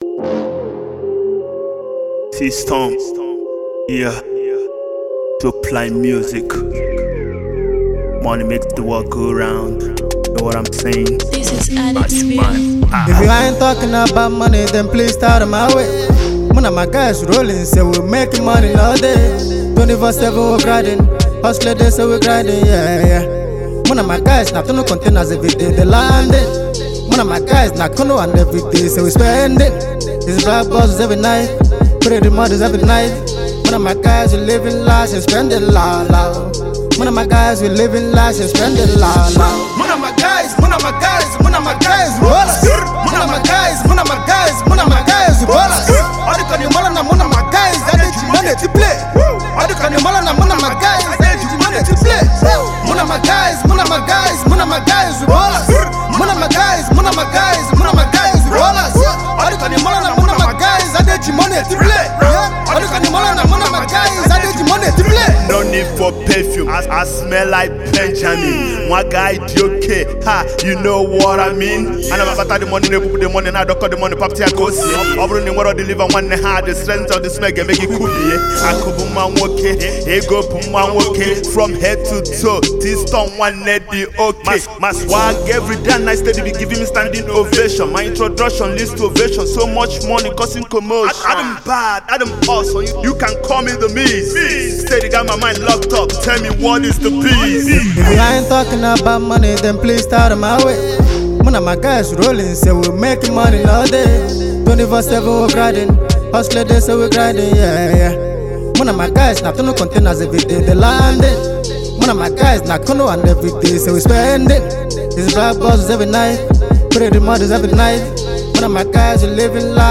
talented rapper
Very dope track with dope punchlines.